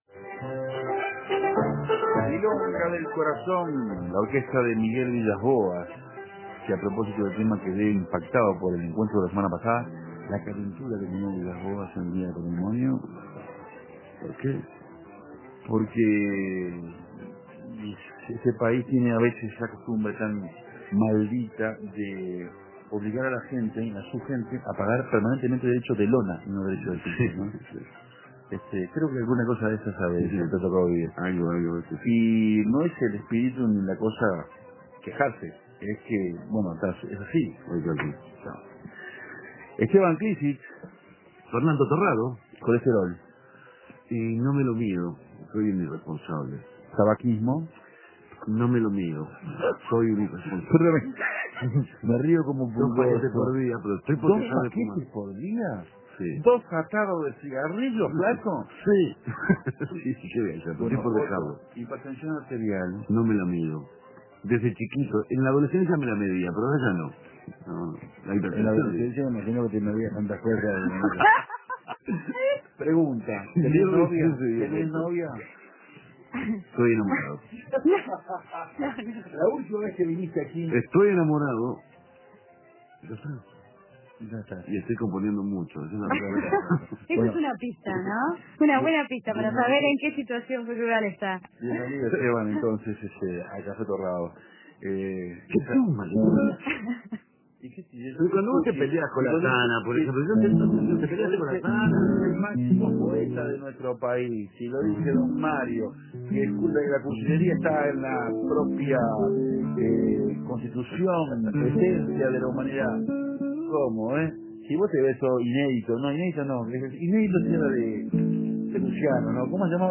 Entre risas y acordes compartieron con los cocafeteros el repertorio que interpretaran en su presentación en Espacio Guambia.